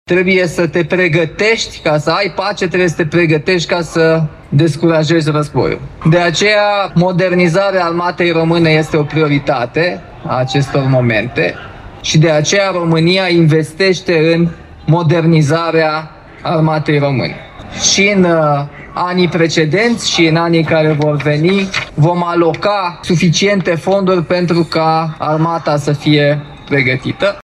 Președintele Nicușor Dan participă astăzi la Iași la evenimentele dedicate Zilei Armatei României.
După cum au transmis colegii noștri de la Radio Iași, alocuțiunea șefului statului a fost întâmpinată cu o serie de huiduieli lansate de un grup de aproximativ 50 de persoane cu megafoane.